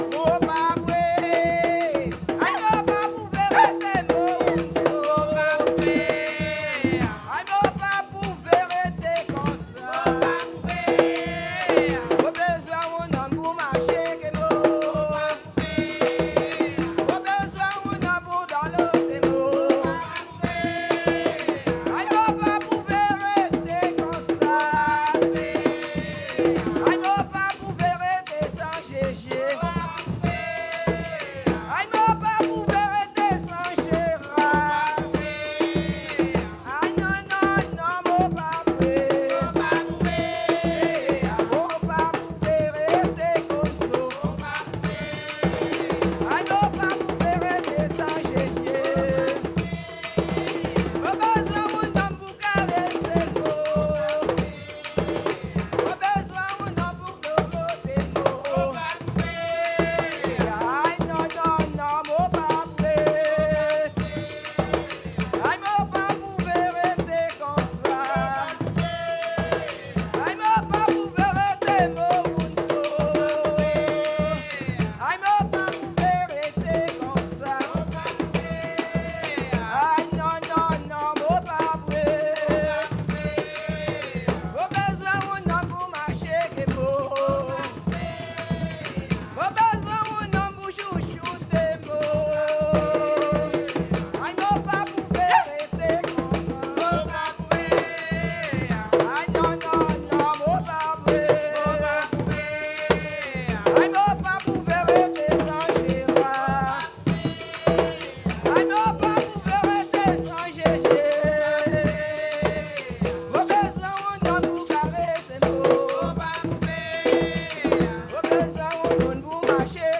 Saint-Georges-de-l'Oyapoc
danse : kasékò (créole)
Pièce musicale inédite